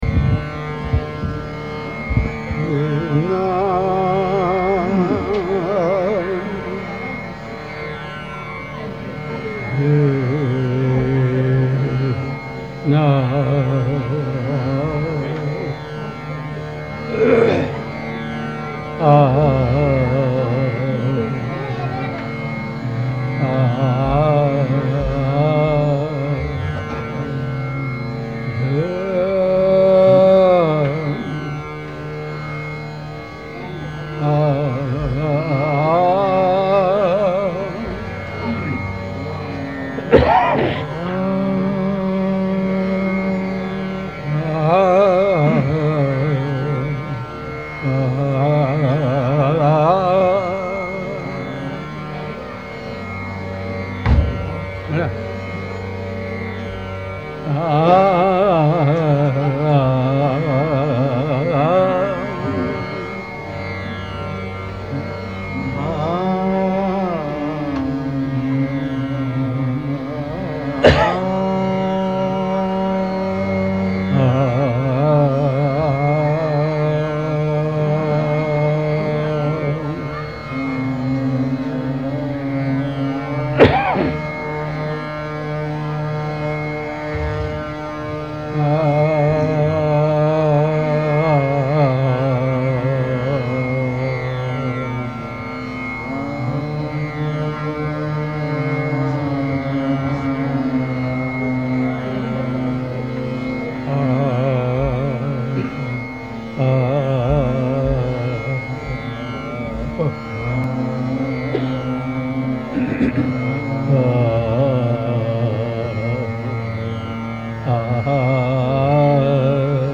in concert Kalavati, Bhairav